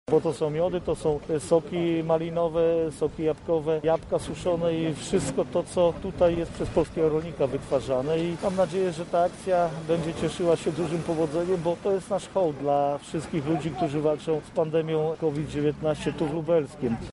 Są to produkty, które są produkowane tu na Lubelszczyźnie– mówi marszałek województwa lubelskiego Jarosław Stawiarski